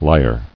[li·ar]